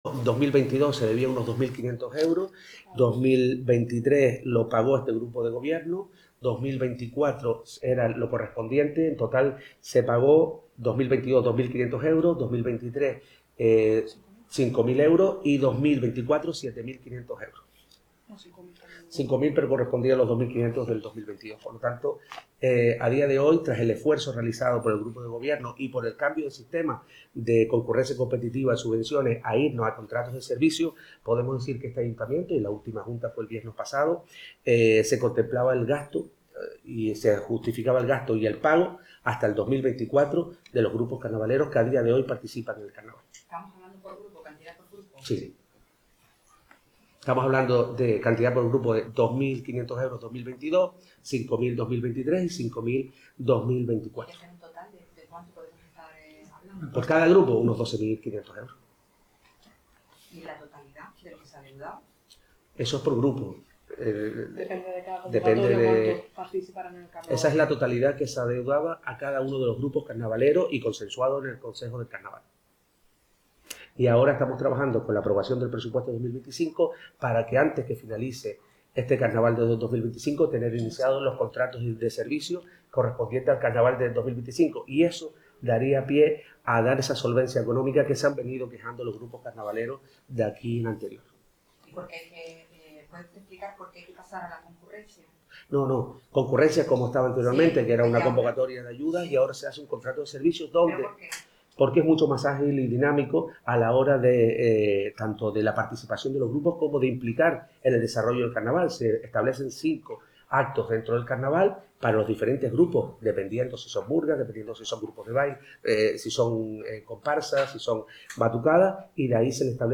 En las preguntas de los medios al término de la rueda de prensa, el alcalde, David de Vera, y la concejala de Festejos, Tacoremi Gutiérrez, detallaron los importes de las subvenciones adeudadas.